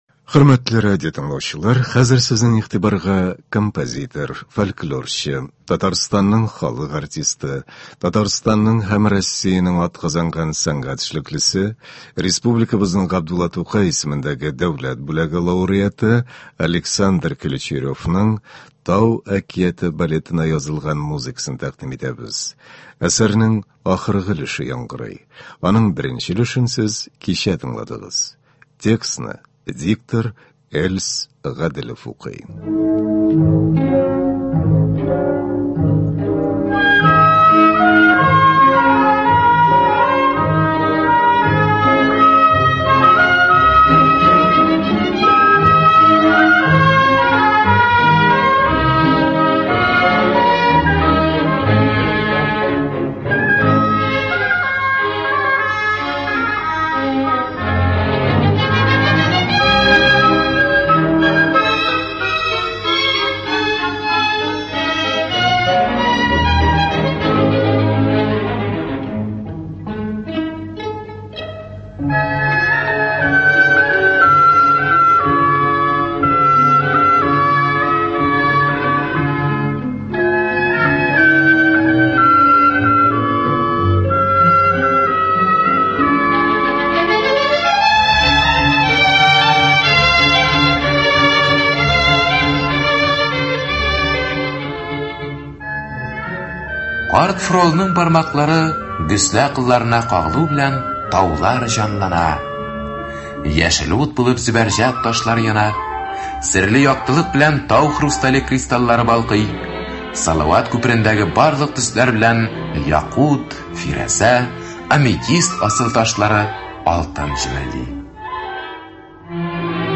Көндезге концерт.
Кичке концерт.